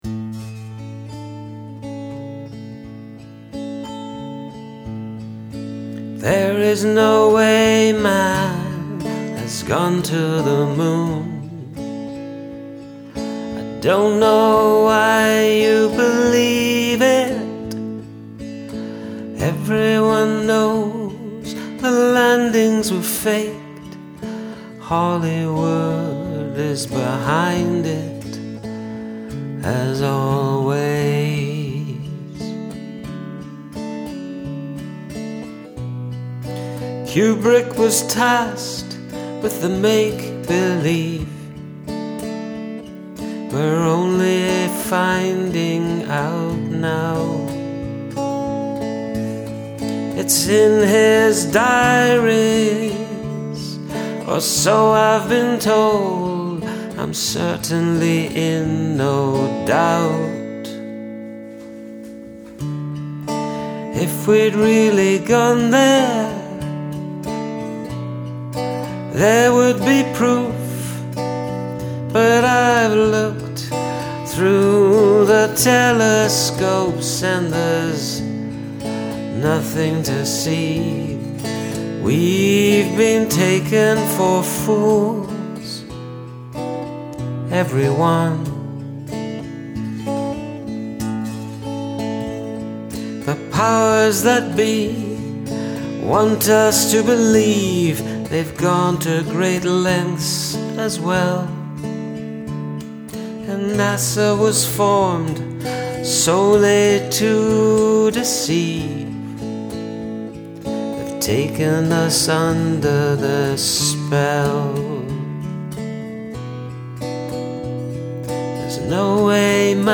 The music sweet uplifting music made me laugh. Vocals spot on!.